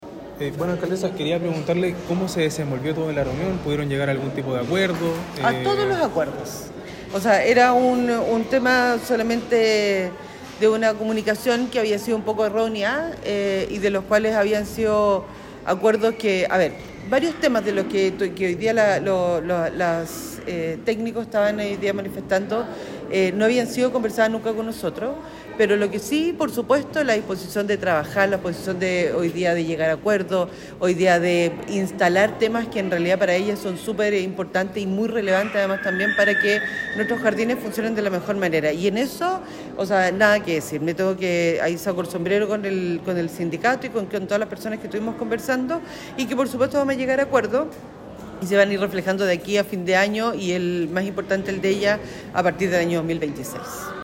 Declaraciones-Isabel-Valenzuela-por-manifestacion.mp3